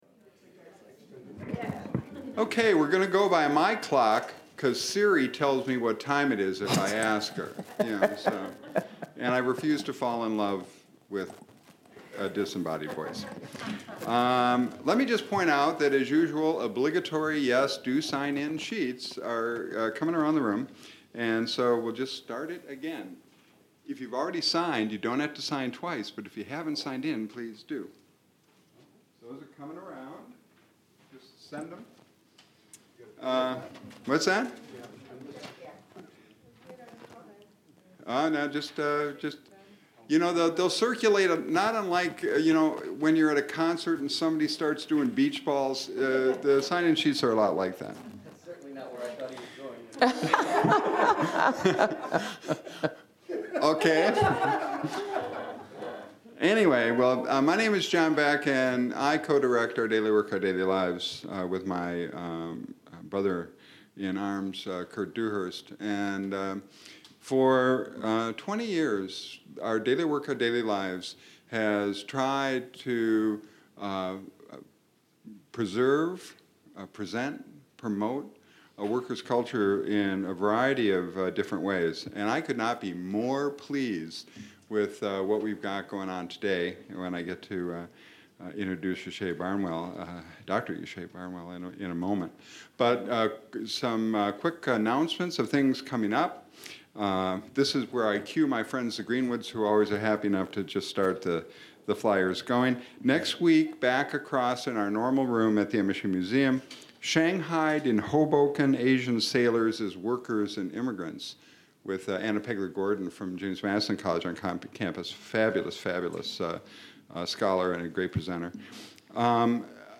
Focusing on mining and textiles, Barnwell says that workplace issues and problems are often reflected in the lyrics of songs, as is unionization and attempts to improve the lives of workers. Barnwell answers questions from the audience.
Held in the MSU Main Library.